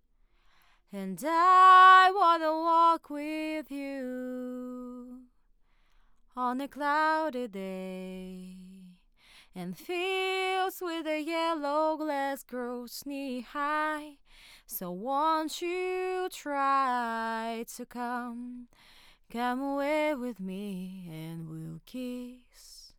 Вот, записано одновременно с одинаковыми настройками, у октавы убрал внутреннюю сетку одну.
Но, сильной разницы в звучании при таком прямом сравнении не нашел:cool:.
Роде как будто чётче и ярче в верхней середине,Октава вроде бы яркий мик,этой яркости что то не слышно,хотя можно добавить верхов,низ порезать,непонятно,может действительно что менять надо из деталей в Октаве.